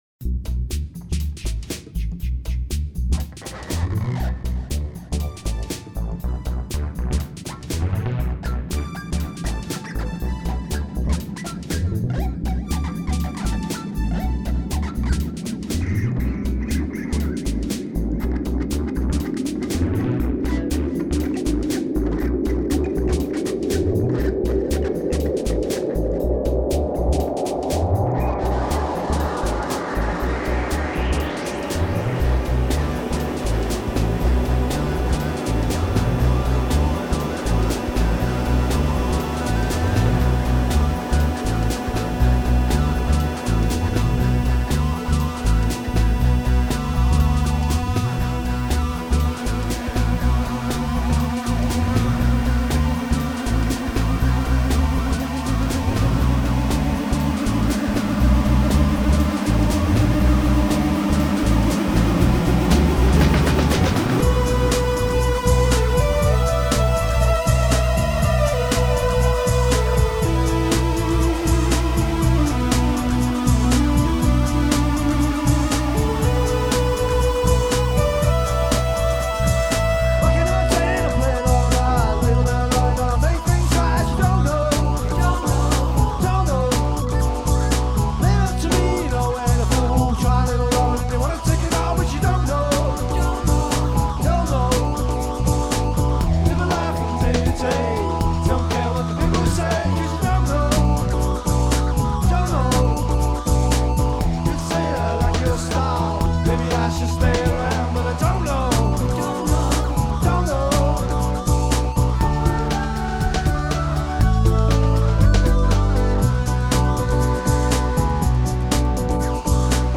(live)
bass, keyboards and lead vocals
guitar
flute and harmony vocals